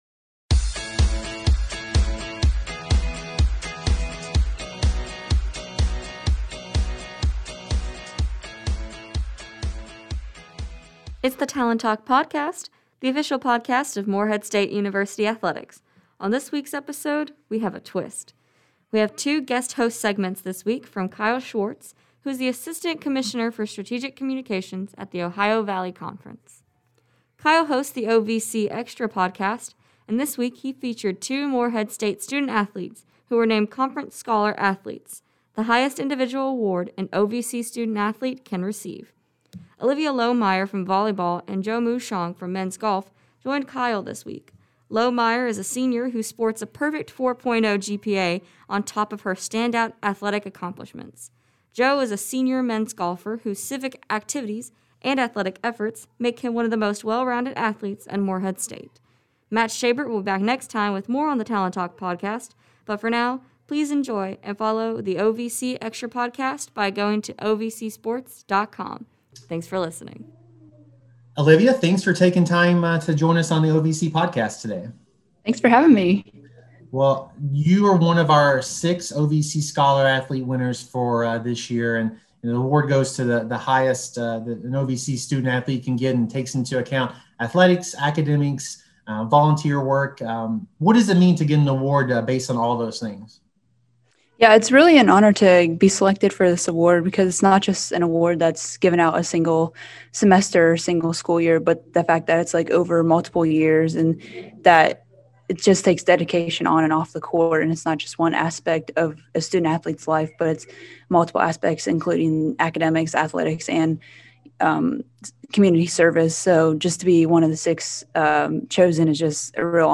We have two guest-host segments from the OVC Extra podcast